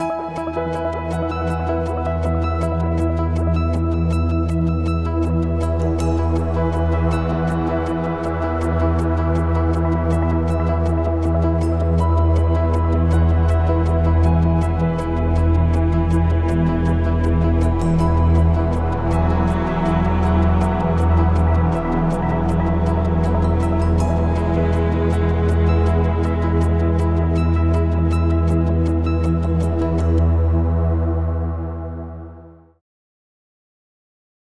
Ambient music pack
Ambient Joy cut 30.wav